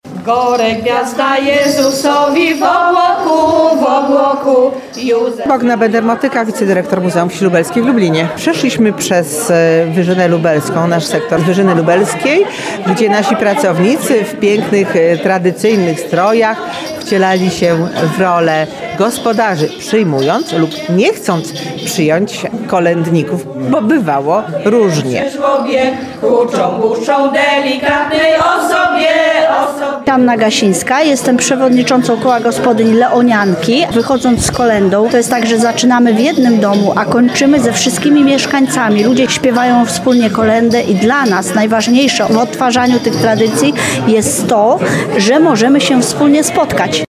Tradycyjne świąteczne przyśpiewki oraz kolędy wybrzmiały w lubelskim skansenie. Okazją do tego było spotkanie i wspólne kolędowanie w Święto Trzech Króli.